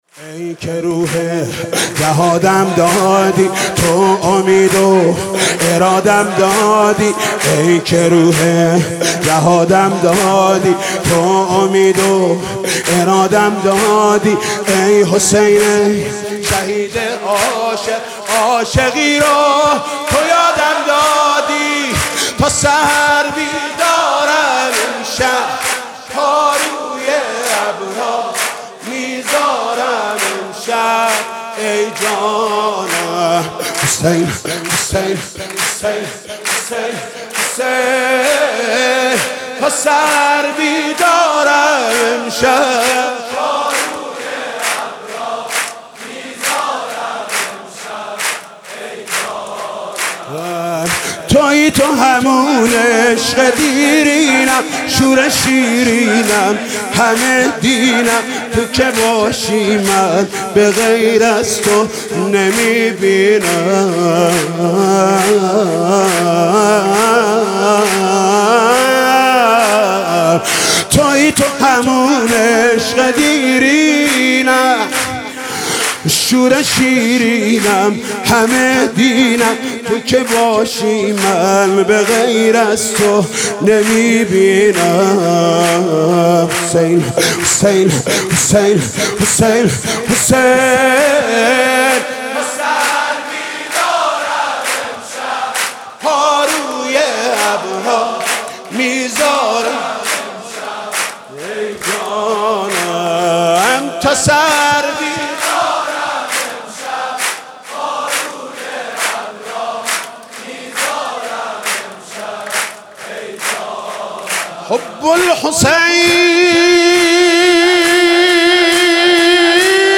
مناسبت : ولادت امام حسین علیه‌السلام
قالب : سرود